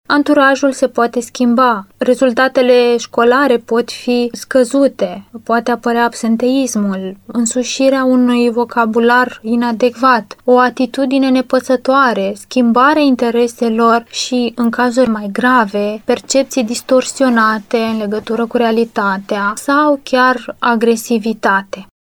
într-un interviu recent, la Radio Constanța